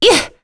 Lewsia_B-Vox_Damage_02.wav